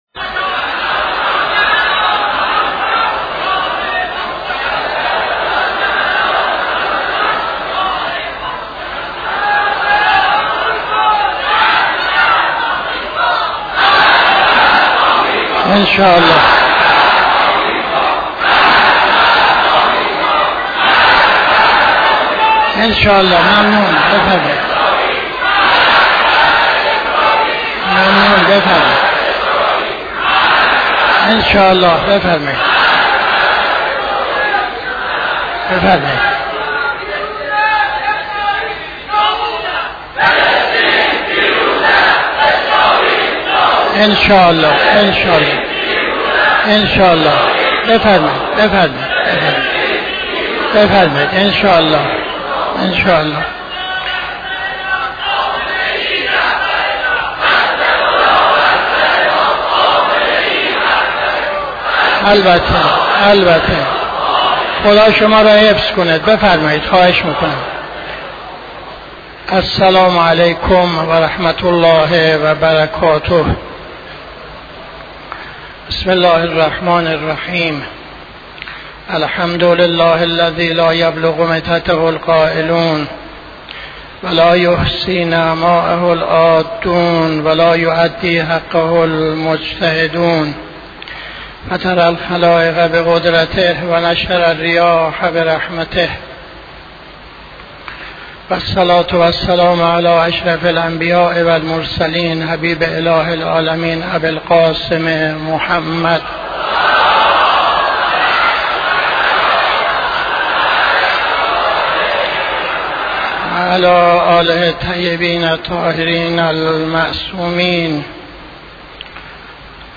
خطبه اول نماز جمعه 11-05-81